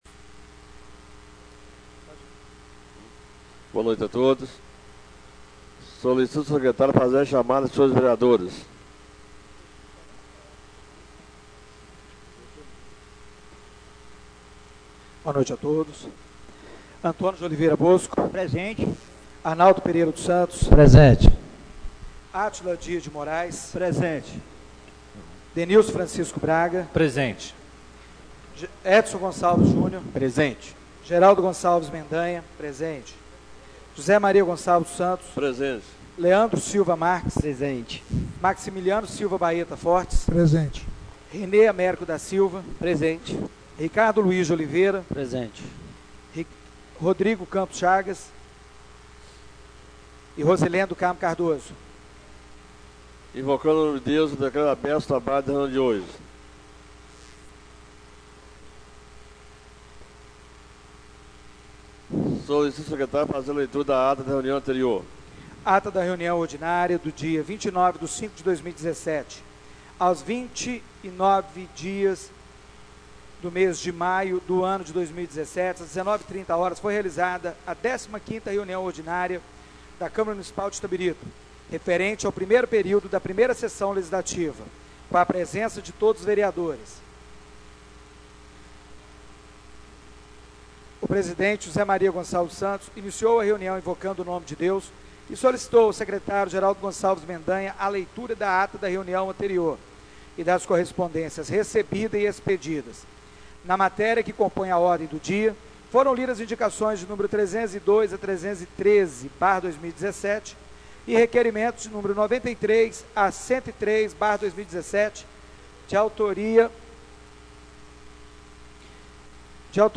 Reunião Ordinária do dia 05/06/2017